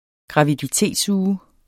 Udtale [ gʁɑvidiˈteˀdsˌuːə ]